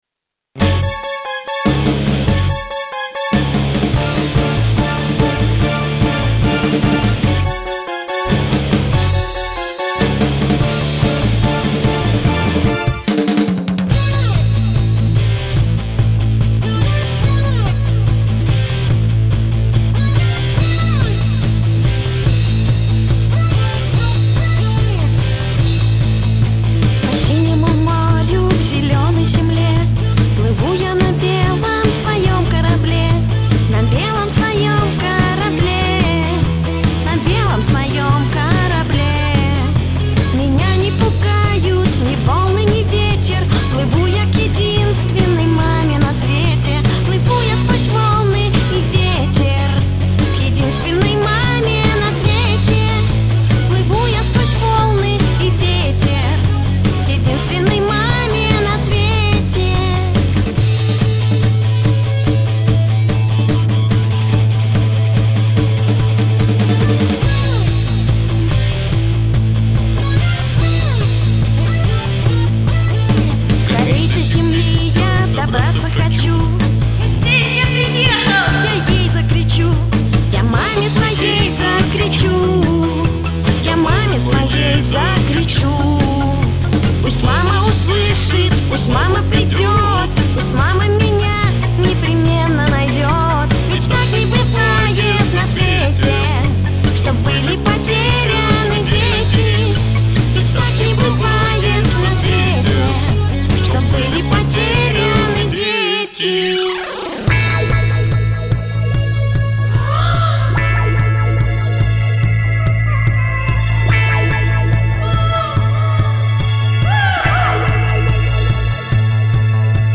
Ремикс на его любимую песню =))) music